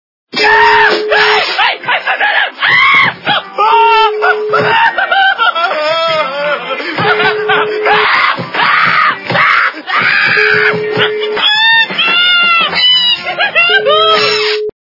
» Звуки » Смешные » Говорящий телефон - Дикий смех
При прослушивании Говорящий телефон - Дикий смех качество понижено и присутствуют гудки.
Звук Говорящий телефон - Дикий смех